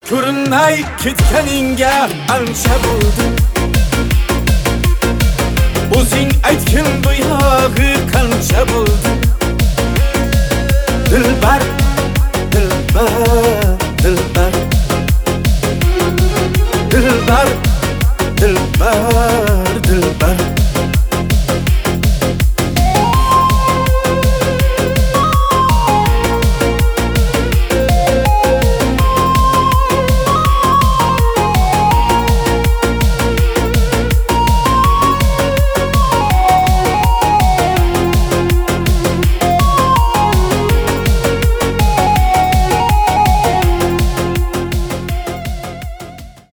танцевальные , узбекские
восточные , поп